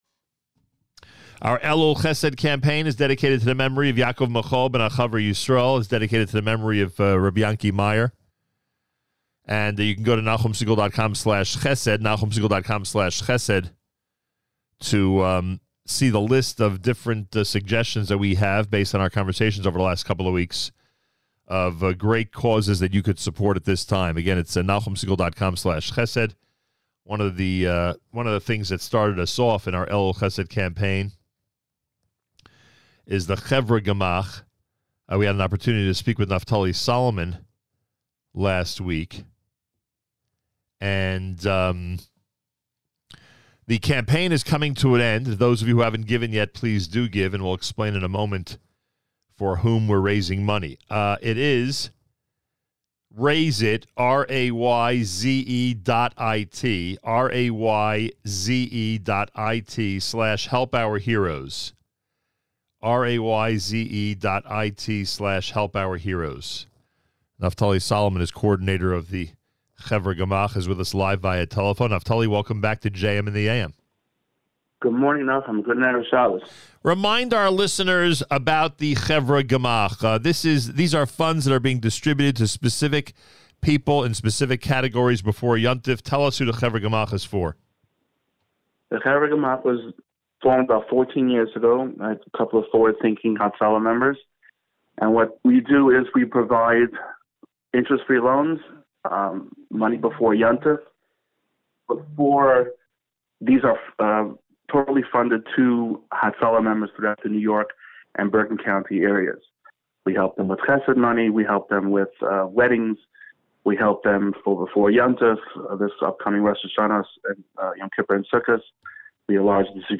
By :  | Comments : Comments Off on Last Few Hours to Support the Help Our Heroes Campaign Going on Now | Category : Interviews, News